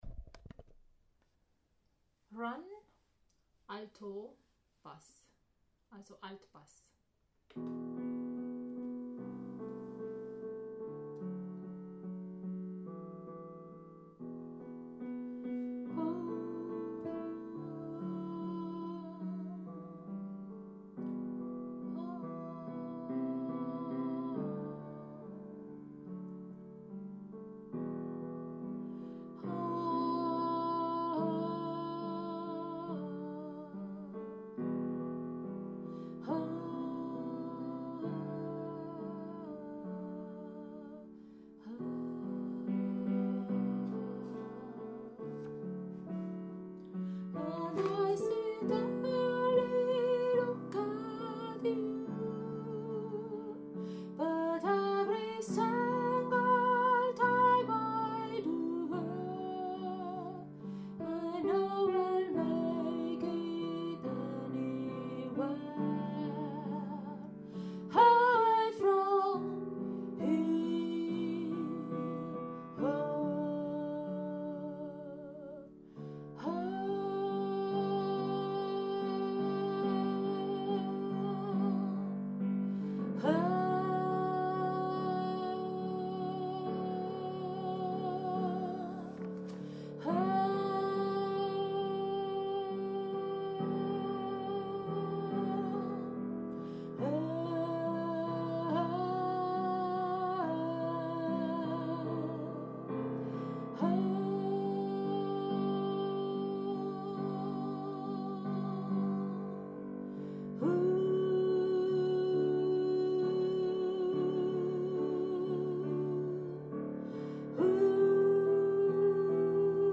Run – Alto/Bass